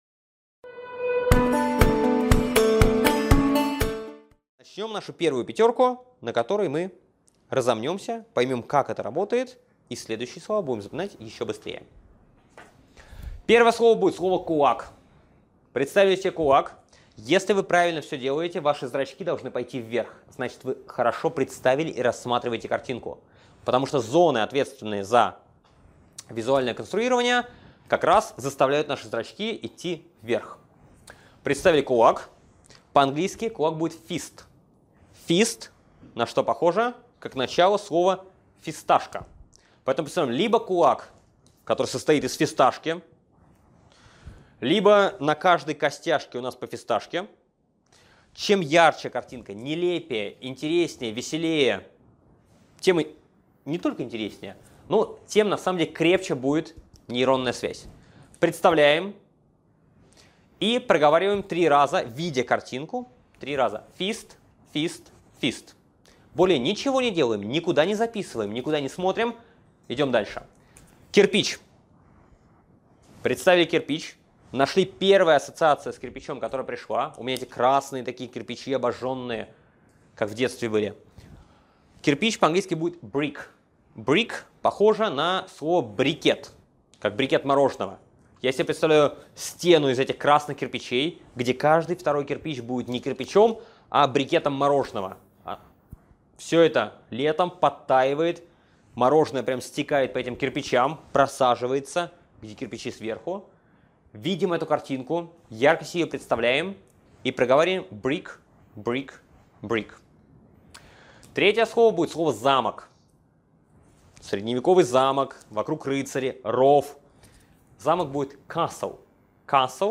Аудиокнига Практика запоминания 20 английских слов | Библиотека аудиокниг